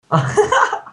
hahaha